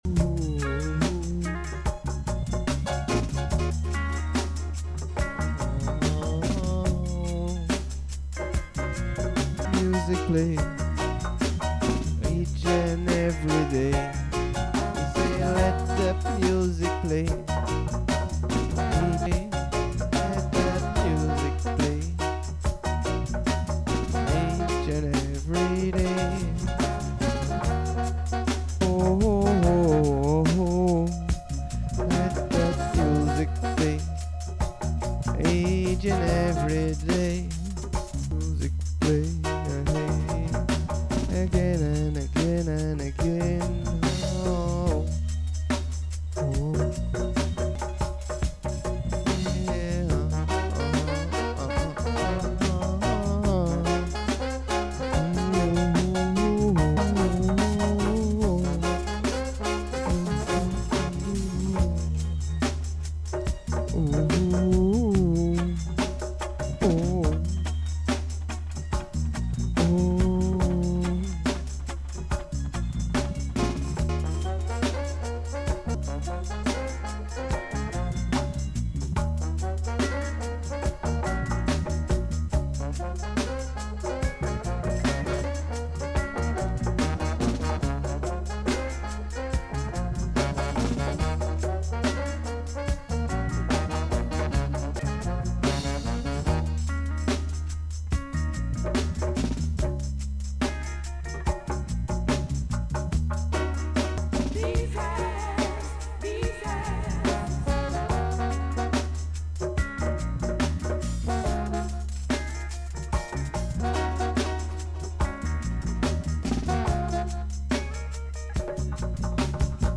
Being One brings a Multi Instrument Vibration, as the 38th Rotation of the Musical Monday Show begins with an Everything Jam Session onna Rootsyard Radio Station.